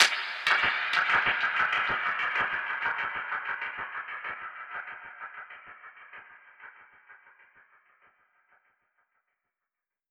Index of /musicradar/dub-percussion-samples/95bpm
DPFX_PercHit_D_95-02.wav